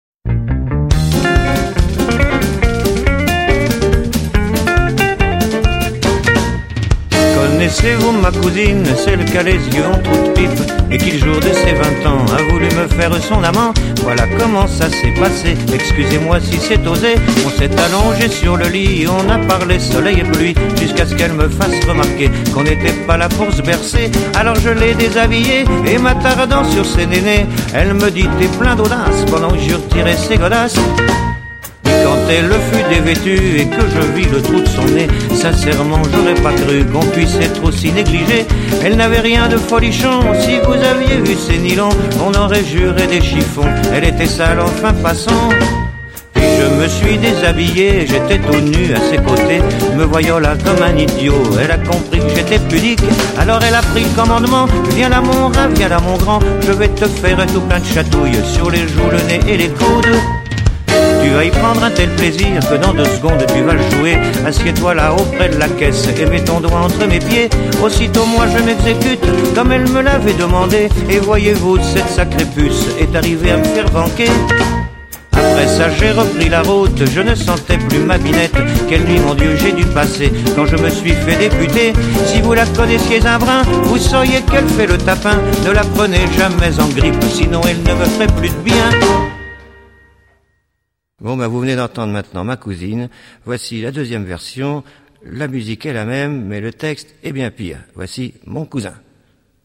marqué par un swing manouche indéniable